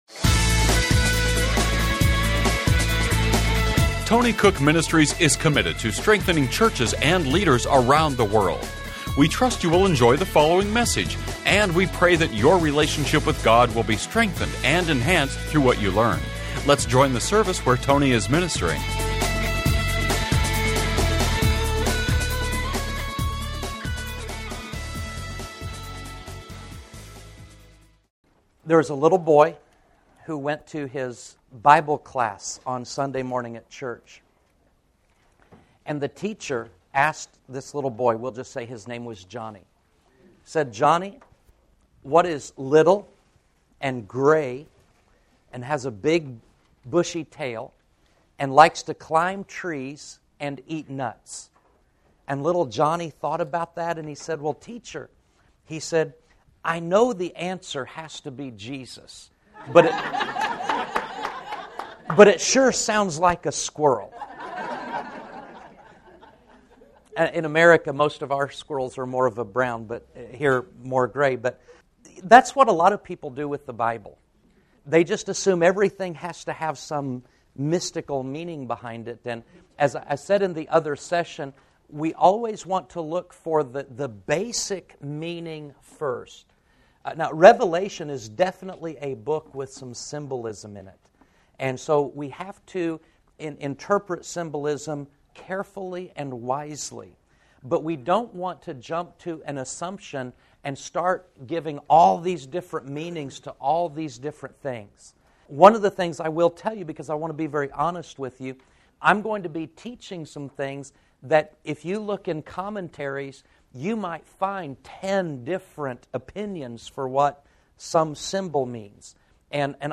Genre: Christian Teaching.